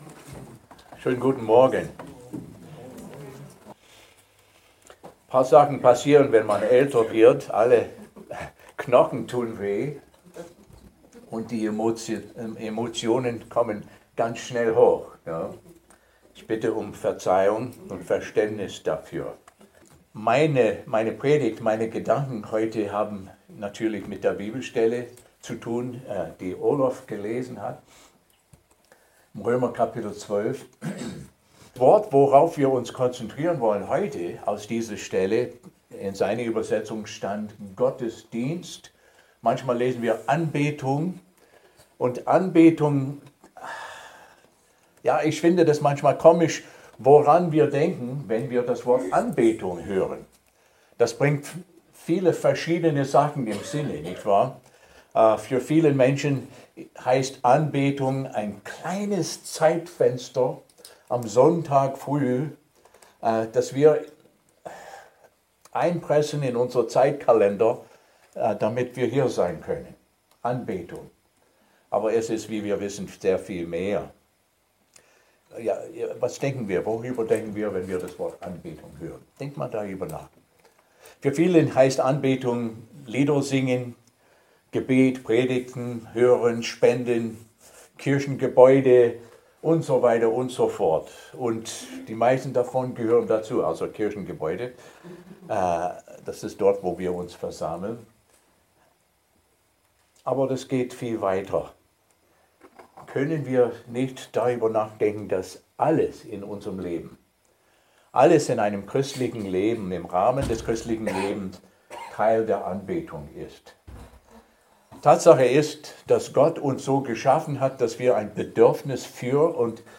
Predigten der Gemeinde Christi Chemnitz zum Nachhören